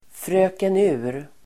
Ladda ner uttalet
Fröken Ur pronomen, the speaking clock Uttal: [frö:ken'u:r] Förklaring: Automatisk telefonsvarare som meddelar exakt tid (se telefonkatalogen under "tidgivning").